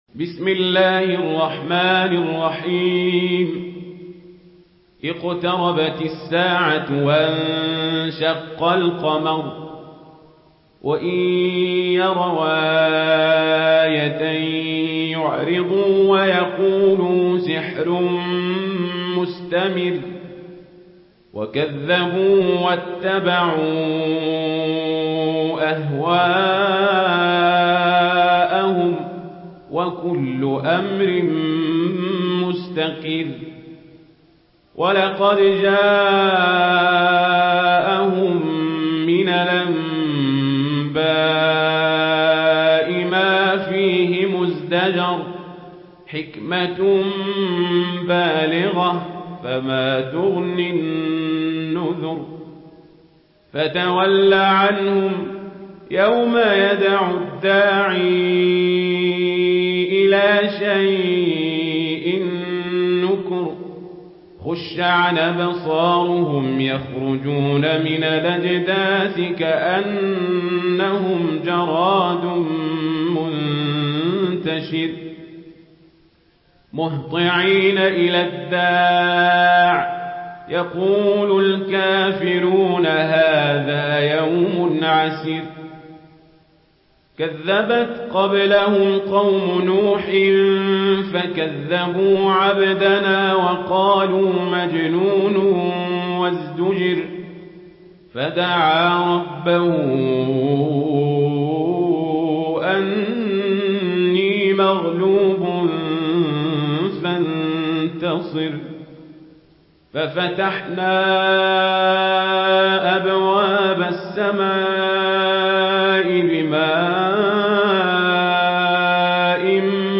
سورة القمر MP3 بصوت عمر القزابري برواية ورش
مرتل ورش عن نافع